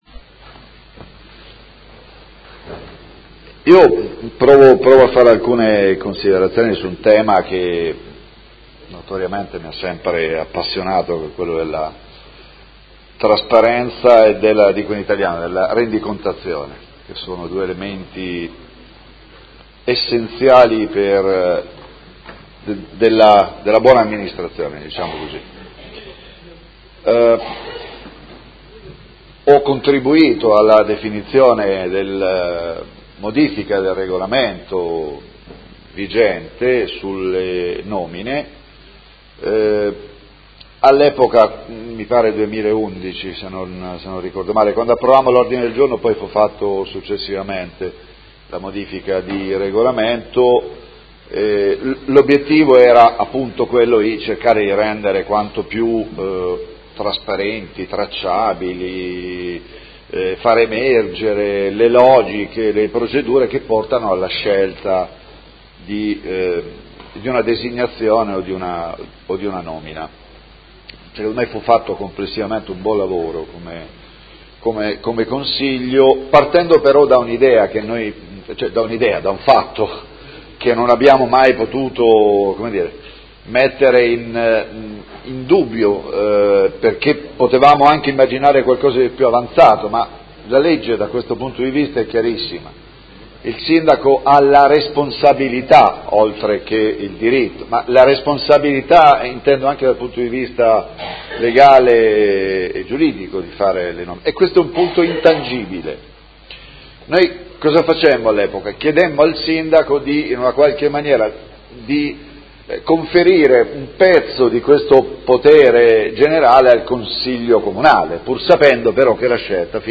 Seduta dell'11/01/2018 Ordine del Giorno presentato dal Movimento cinque Stelle avente per oggetto: Maggior coinvolgimento del Consiglio Comunale nel percorso delle nomine